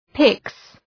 Προφορά
{pıks}